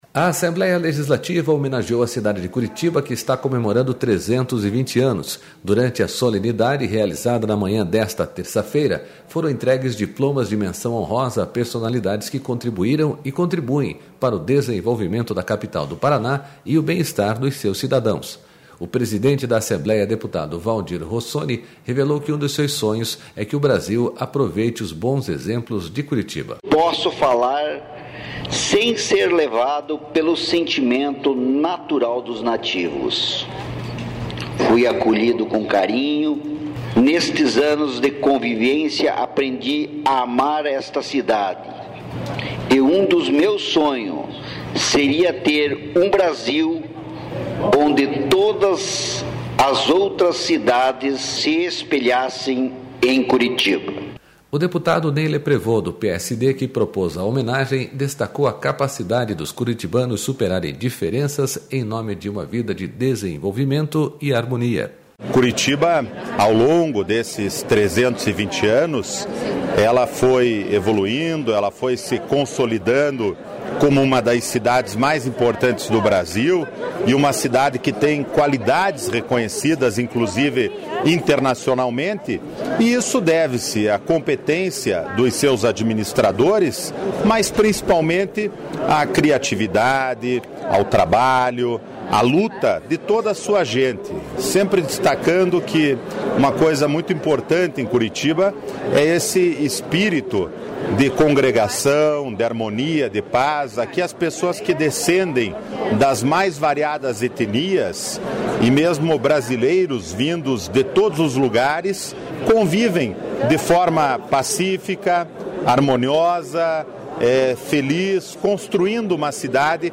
Durante a solenidade, realizada na manhã desta terça-feira, foram entregues diplomas de Menção Honrosa a personalidades que contribuíram e contribuem para o desenvolvimento da Capital do Paraná e o bem-estar de seus cidadãos.//
O presidente da Assembleia, deputado Valdir Rossoni, revelou que um dos seus sonhos é de que o Brasil aproveite os bons exemplos de Curitiba.//
SONORA FERNANDA RICHA